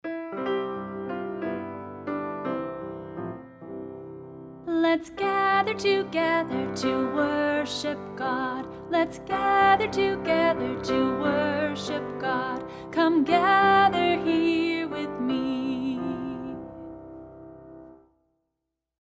a simple song with four verses used at transition times